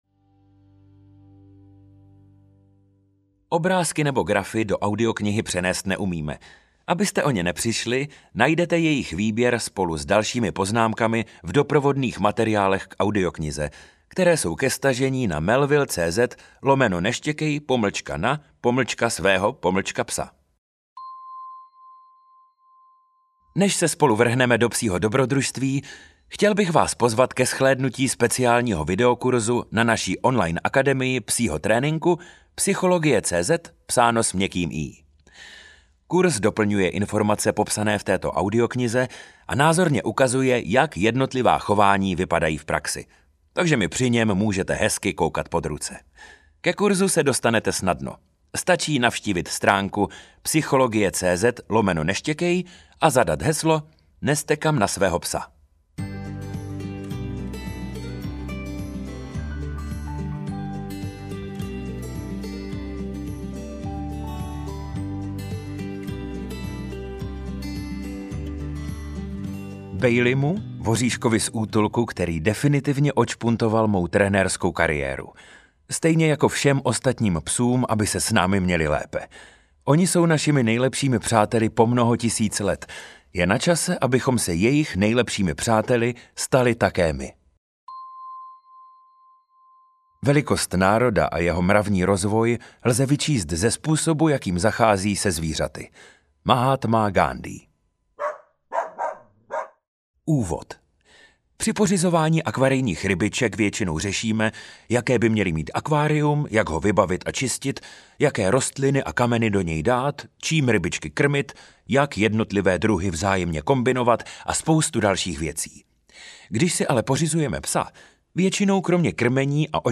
Neštěkej na svého psa audiokniha
Ukázka z knihy